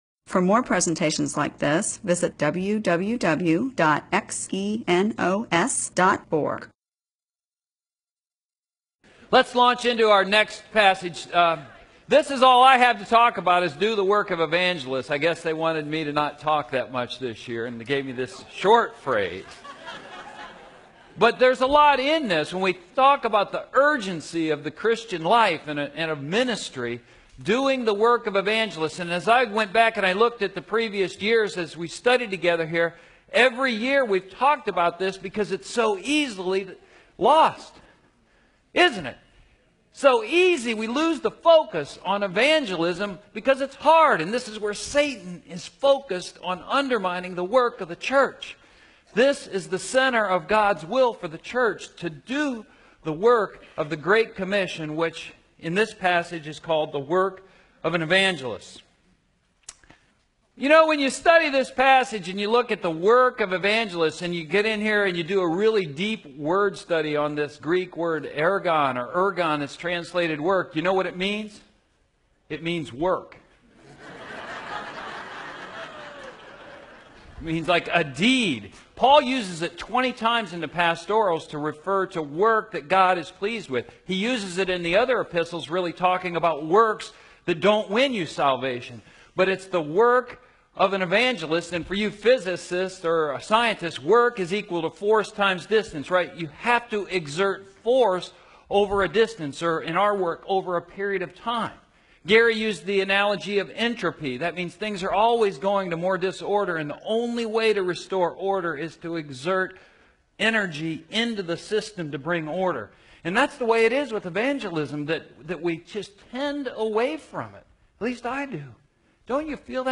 MP4/M4A audio recording of a Bible teaching/sermon/presentation about 2 Timothy 4:5.